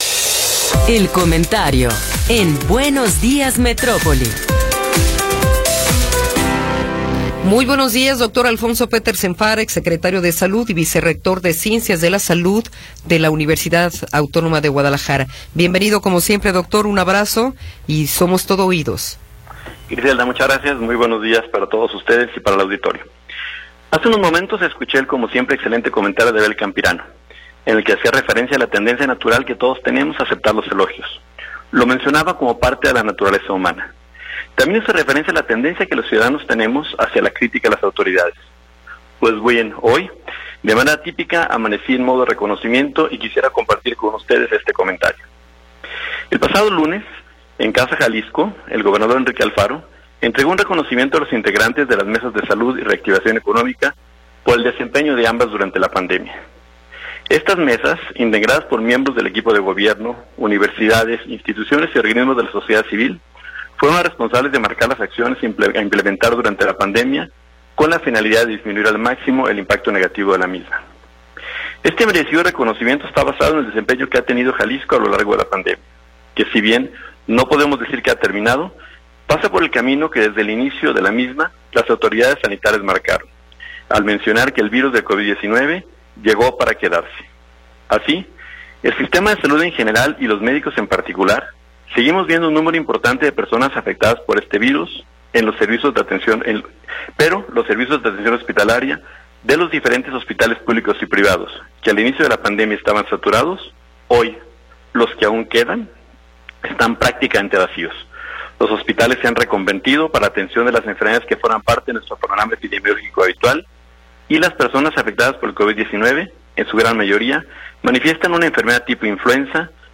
Comentario de Alfonso Petersen Farah
El Dr. Alfonso Petersen Farah, vicerrector de ciencias de la salud de la UAG y exsecretario de salud del estado de Jalisco, nos habla sobre el reconocimiento a los integrantes de las mesas de Salud y Reactivación Económica por su trabajo durante la pandemia.